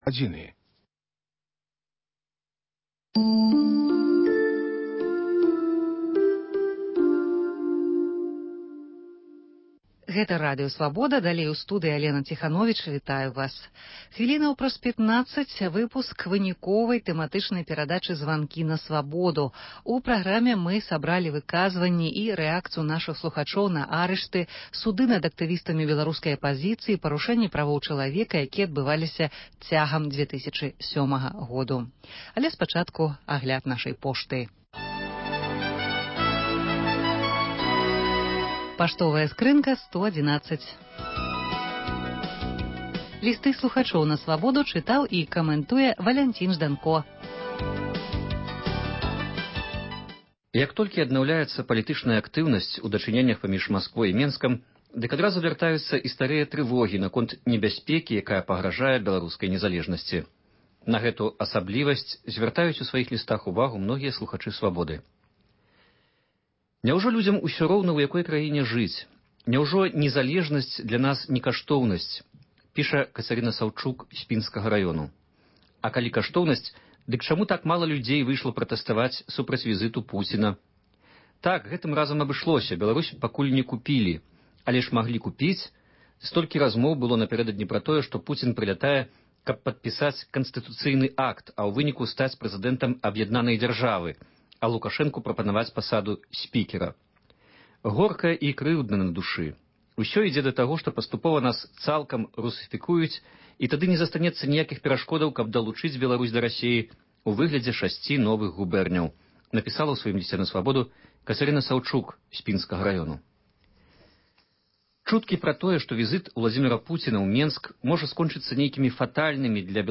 Слухачы аб рэпрэсіях у Беларусі